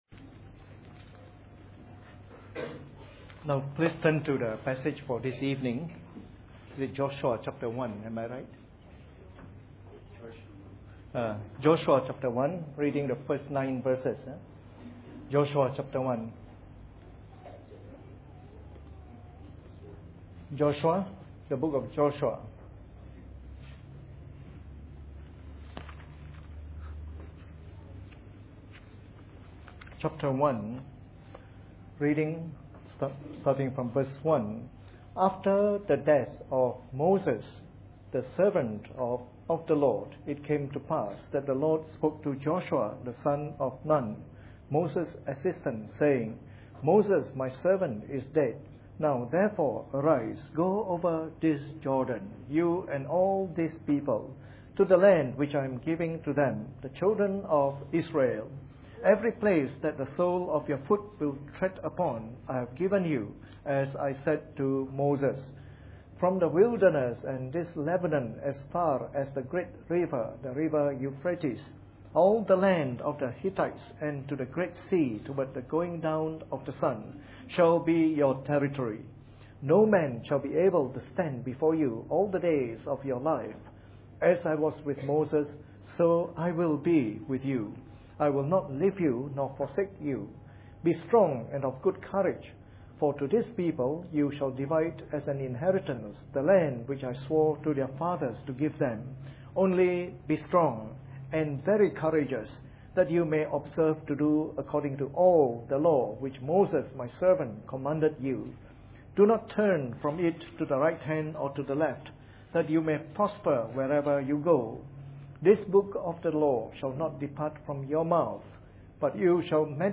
Preached on the 14th of September 2011.